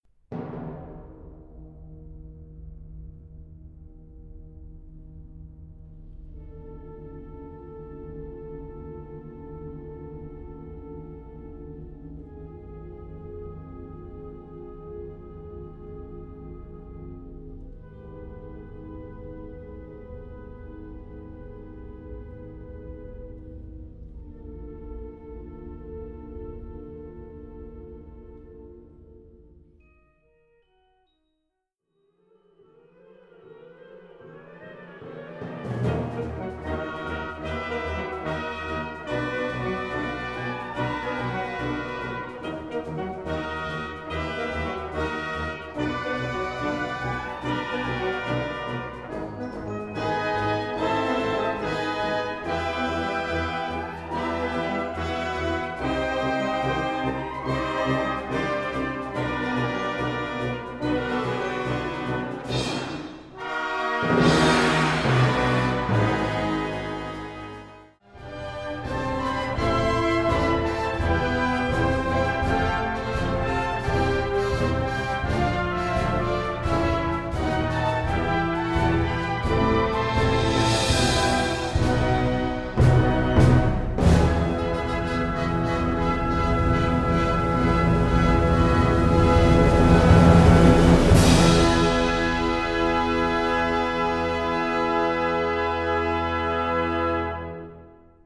Catégorie Harmonie/Fanfare/Brass-band
Sous-catégorie Comédies musicales
Instrumentation Ha (orchestre d'harmonie)